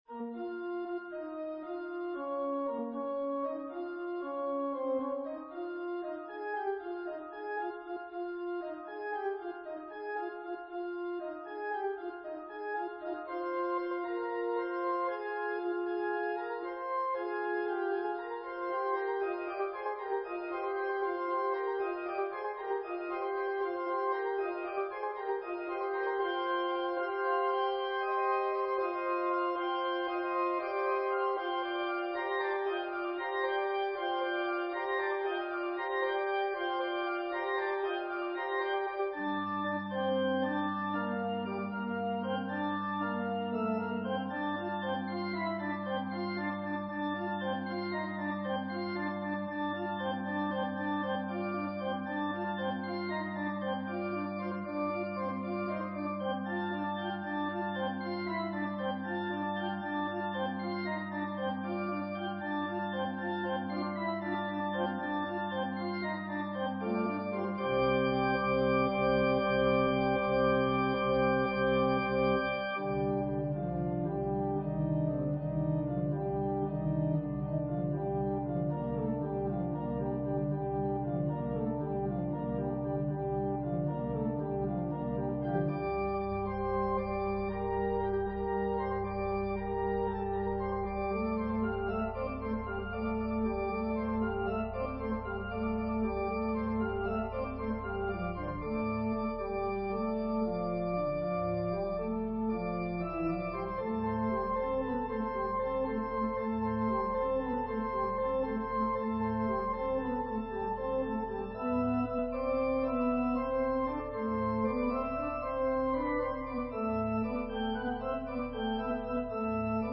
An organ solo arrangement.
It is a Christmas song depicting the jubilation of the Three Magi when they first saw the star in the east pointing the way to the Christ child.
Voicing/Instrumentation: Organ/Organ Accompaniment We also have other 29 arrangements of " Once, In Royal David's City ".